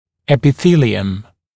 [ˌepɪ’θiːlɪəm][ˌэпи’си:лиэм]эпителий